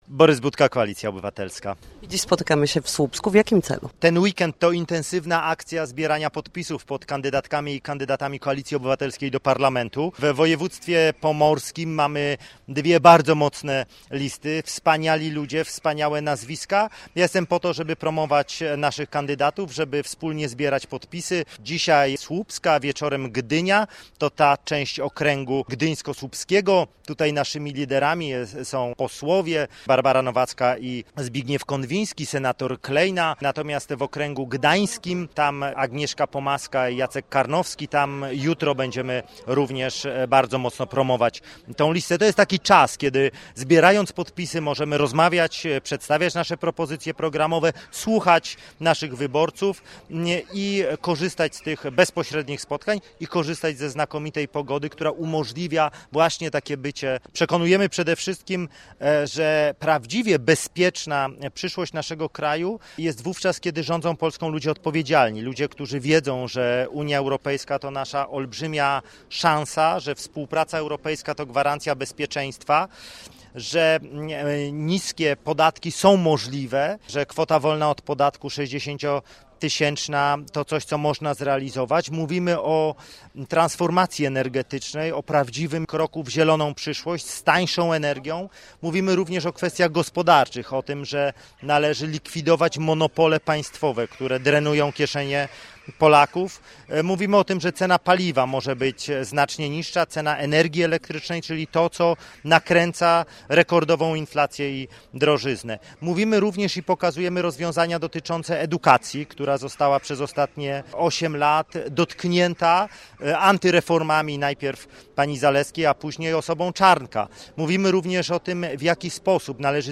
Zorganizowano konferencję prasową, podczas której Borys Budka ogłosił zbieranie podpisów
Głos podczas konferencji zabrali także poseł ze Słupska Zbigniew Konwiński oraz senator Kazimierz Kleina.